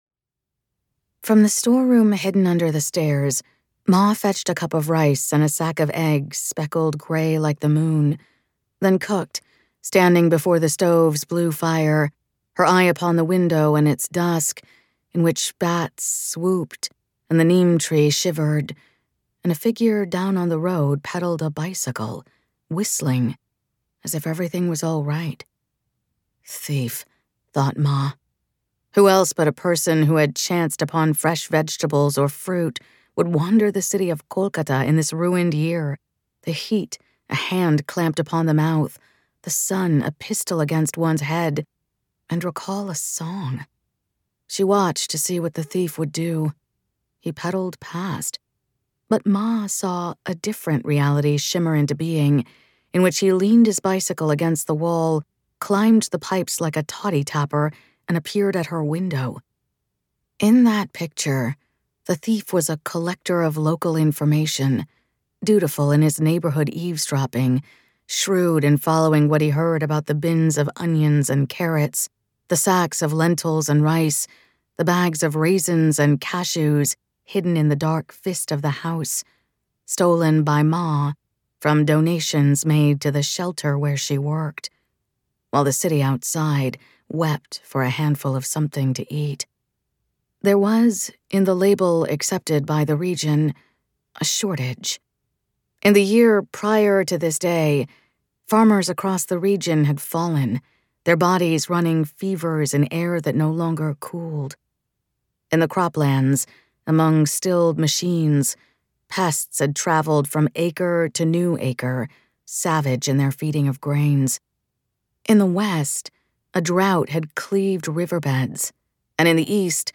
Audiobook sample